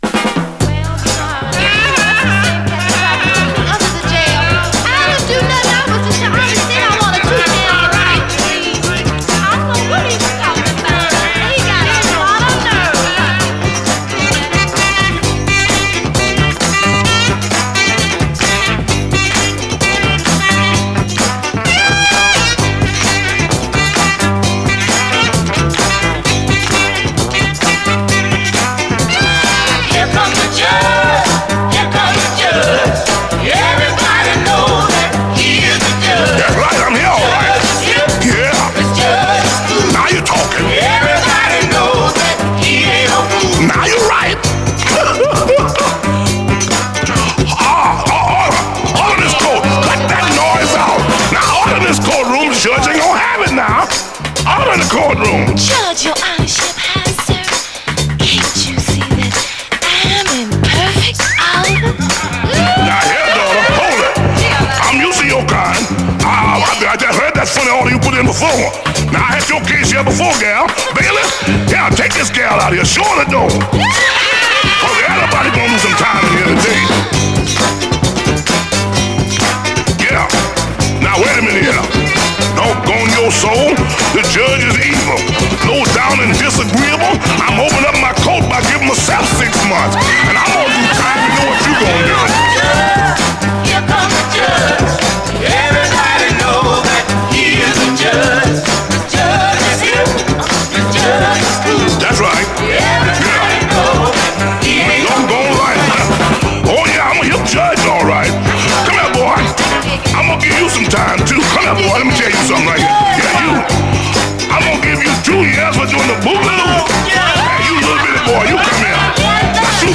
60's SOUL, SOUL, 7INCH
重量級ファンク